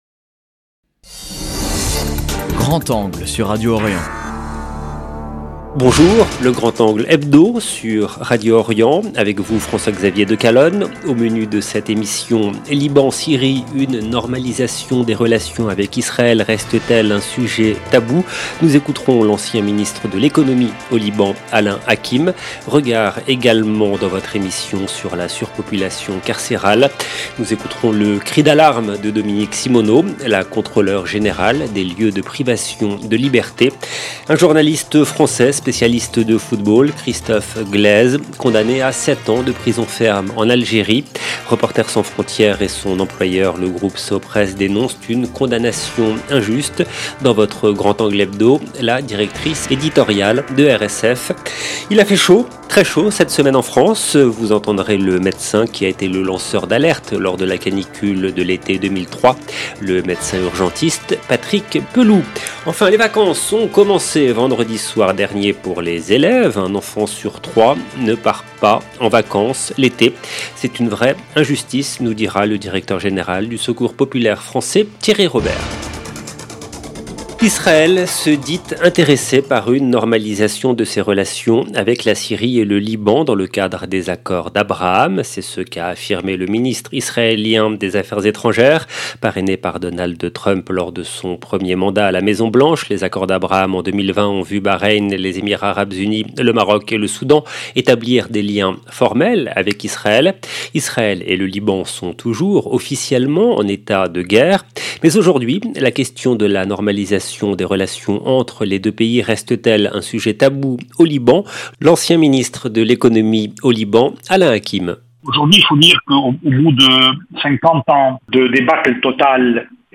Le grand angle hebdo sur Radio Orient
Nous écouterons l’ancien ministre de l’Économie au Liban Alain Hakim. -Regard également dans votre émission sur la surpopulation carcérale.